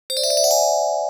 keyAppears.wav